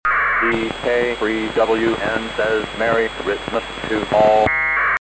ANDE Voice Synthesizer: ANDE has the text-to-speech synthesizer so that it can speak packets sent to it.
It ignores the AX.25 packet header and also the APRS overlaying protocol and converts all of that overhead to simply "XXXXX says:" and then continues with the original packet text converted to voice.